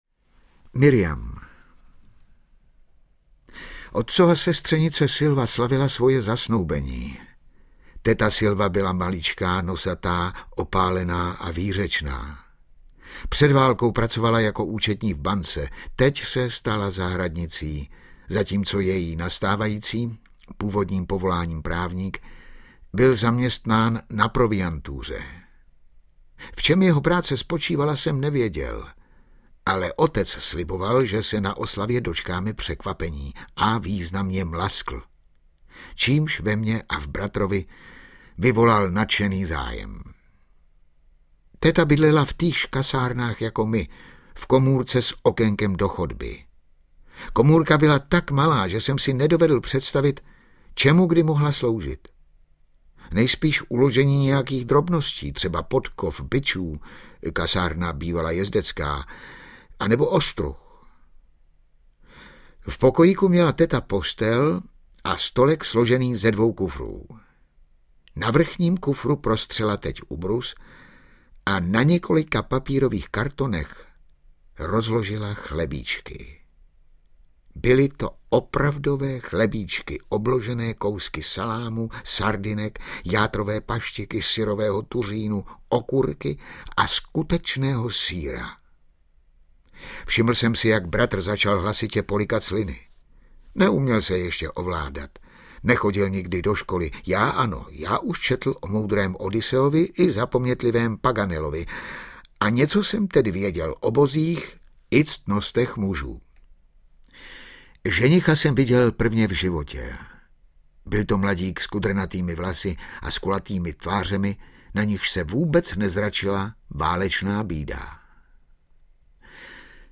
Čte: Ladislav Mrkvička